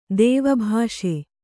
♪ dēva bhāṣe